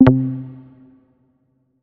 Оповещение об очистке хранилища